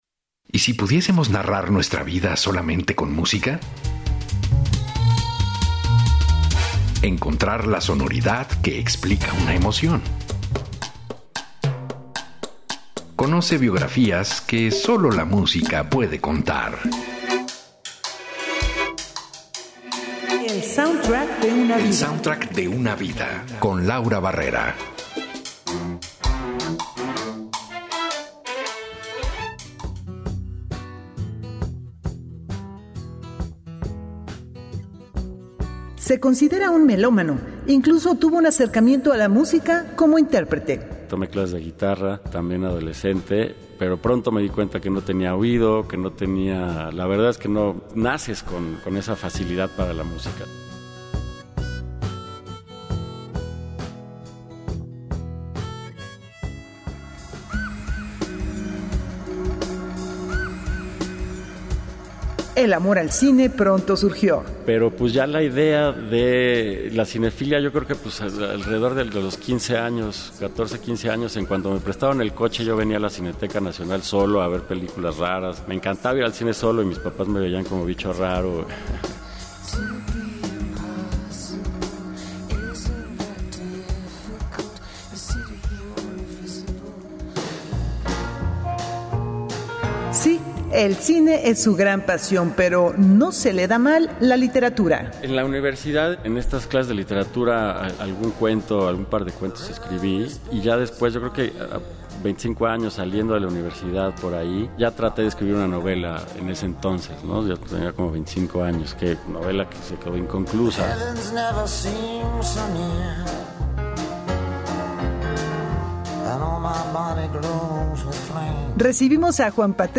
productor de cine y televisión nos acompaña para hablar de diez momentos en su vida, acompañados de 10 melodías.